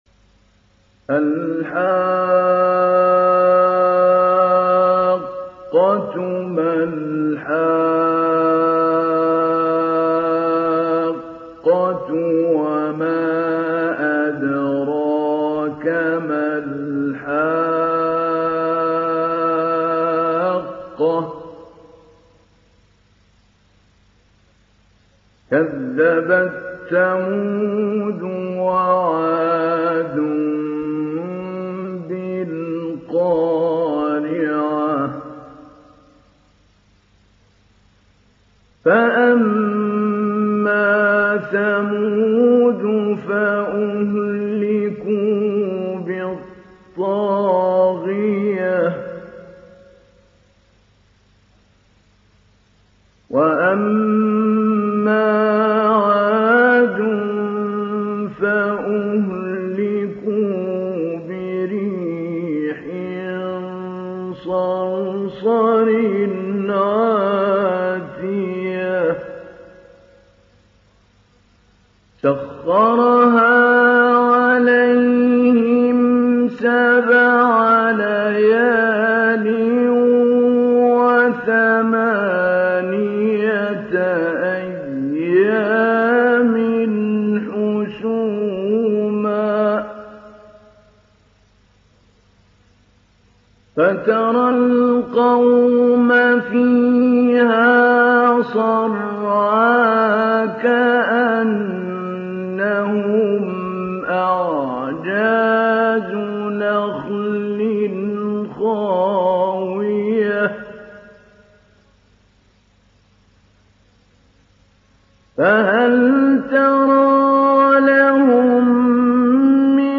Surah Al Haqqah Download mp3 Mahmoud Ali Albanna Mujawwad Riwayat Hafs from Asim, Download Quran and listen mp3 full direct links
Download Surah Al Haqqah Mahmoud Ali Albanna Mujawwad